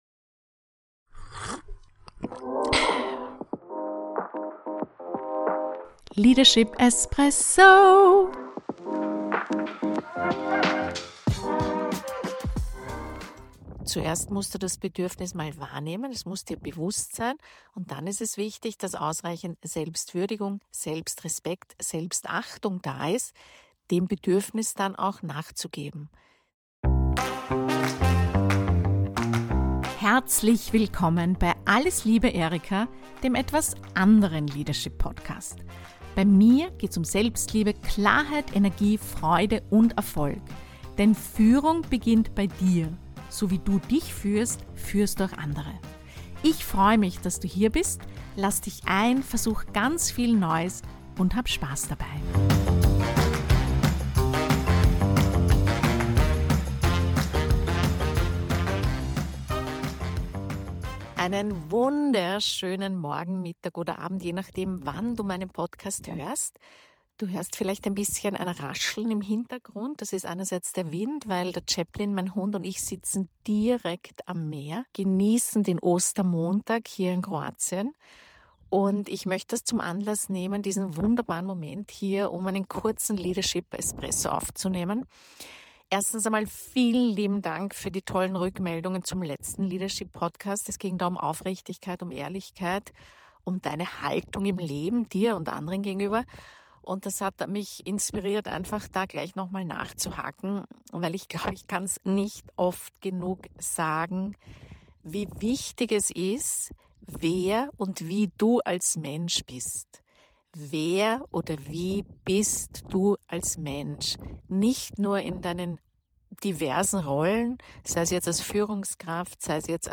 In dieser Folge nehme ich dich mit ans Meer – mitten in meine Workation mit Chaplin.